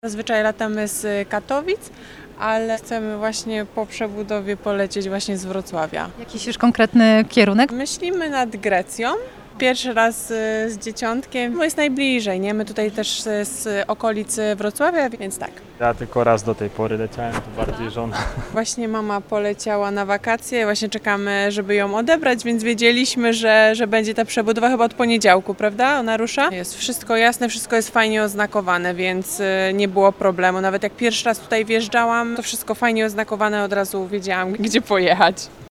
Zapytaliśmy pasażerów o wrażenia. Zapowiadają że chętnie skorzystają z zimowych połączeń.